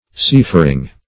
seafaring - definition of seafaring - synonyms, pronunciation, spelling from Free Dictionary
Seafaring \Sea"far`ing\, a.